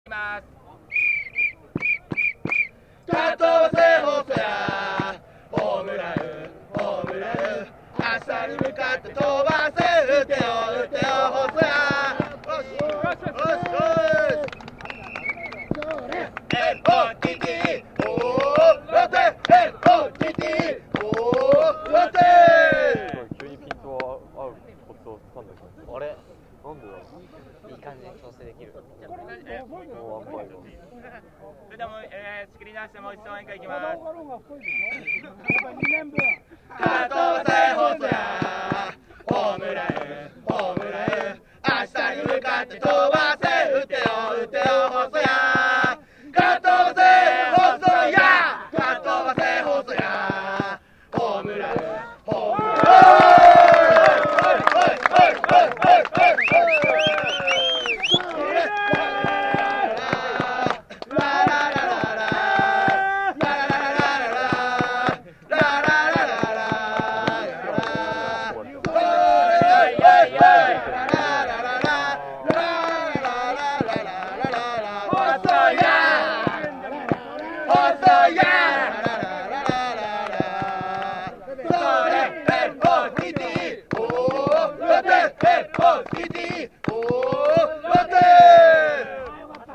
５９ 細谷圭 H19 3/4 浦和
応援歌